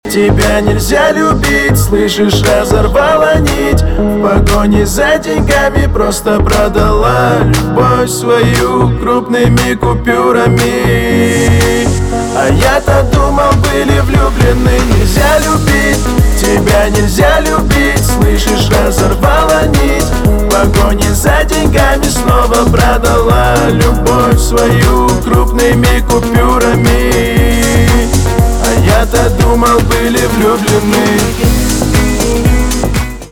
кавказские
грустные
печальные , битовые